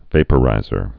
(vāpə-rīzər)